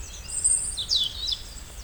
Vogelstimmen: Zaunkönig,
Rotkehlchen,
Rotkehlchen.wav